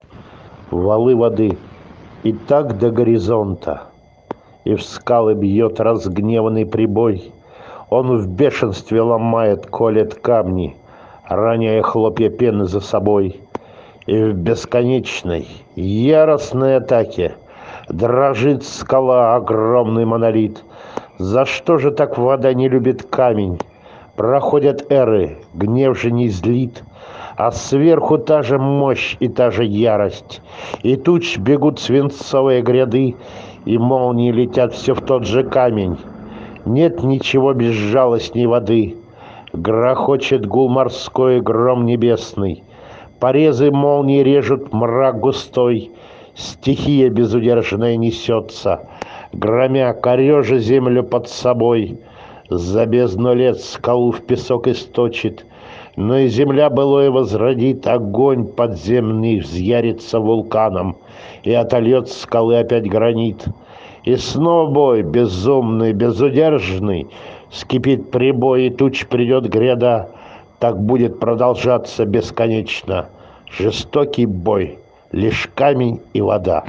в авторском исполнении